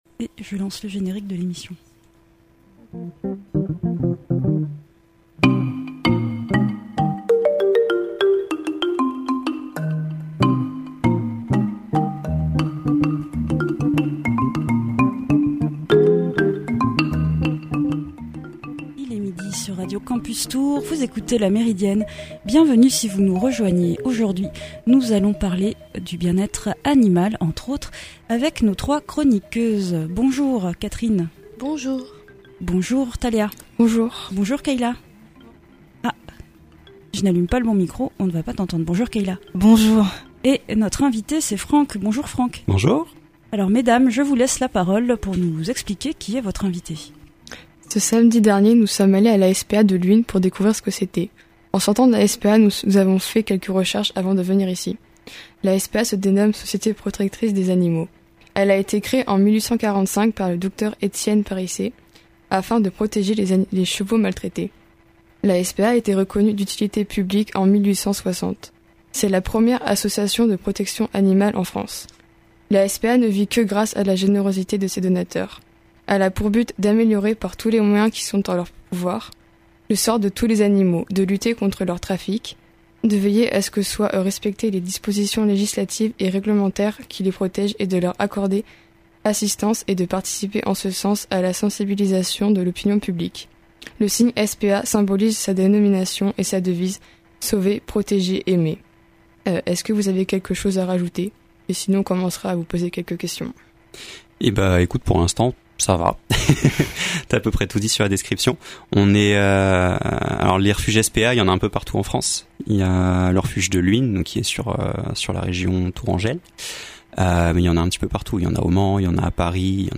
Nos trois chroniqueuses